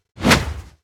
x1_battle_longnv_attack.wav